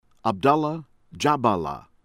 DEMBRI, MOHAMED-SALAH moh-HAH-mehd   sah-LAH   DEHM-bree